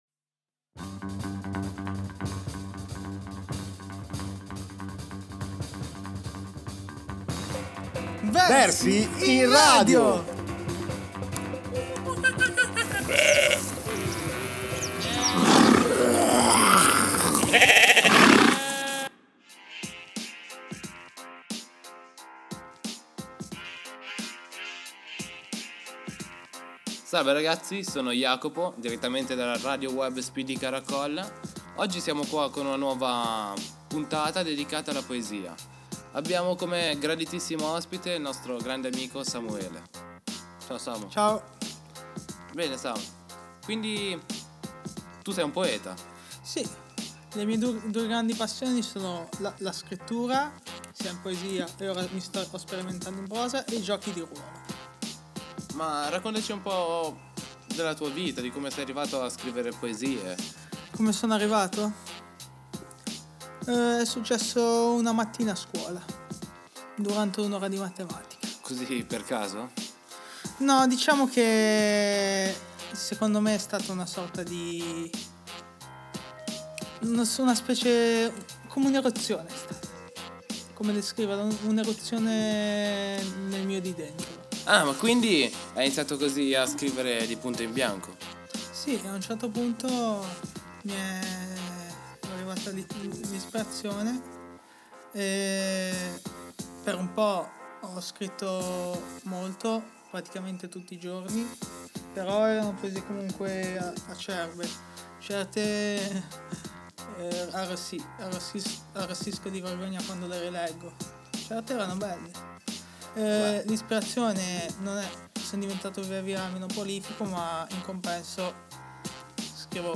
Versi in Radio....un'esilerante programma sulla poesia.